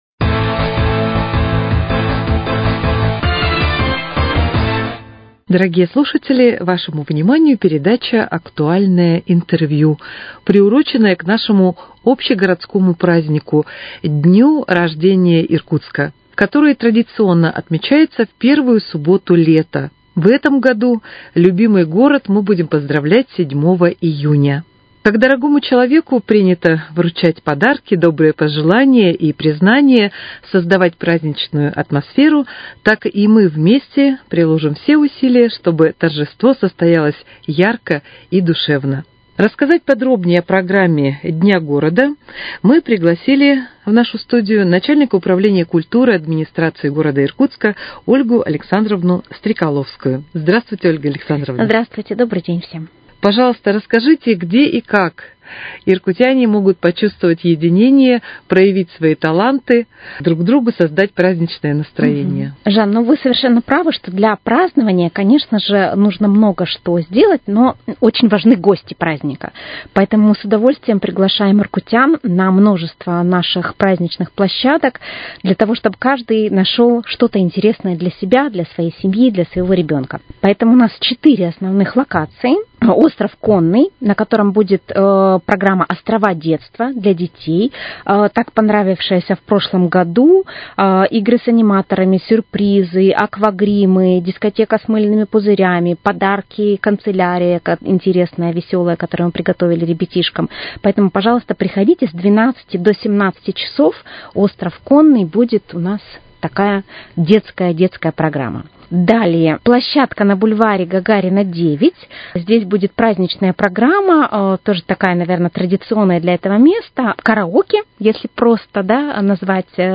Актуальное интервью: О программе праздничных мероприятий ко Дню города